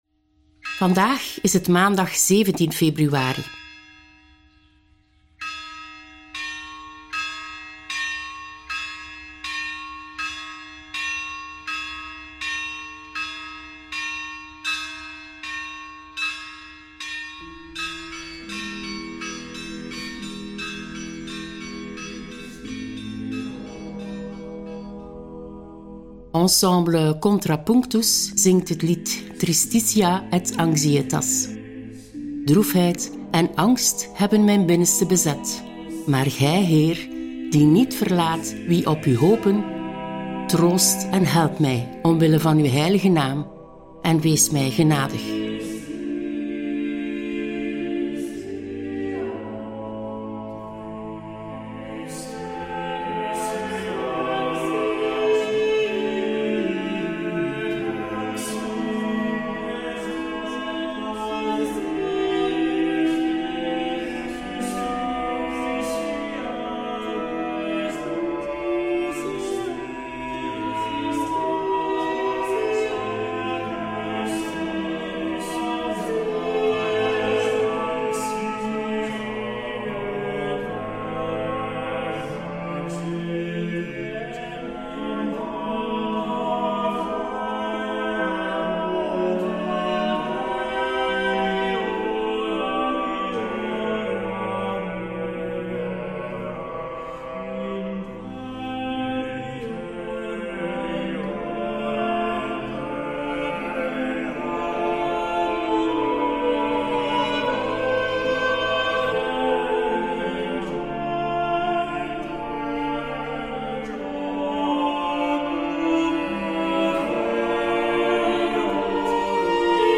Bidden Onderweg brengt je dichter bij God, met elke dag een nieuwe gebedspodcast. In de meditaties van Bidden Onderweg staan Bijbelteksten central. De muzikale omlijsting, overwegingen y begeleidende vragen helpen je om tot gebed te komen.